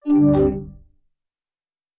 Alert (1).wav